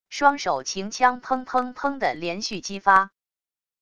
双手擎枪砰砰砰的连续击发wav音频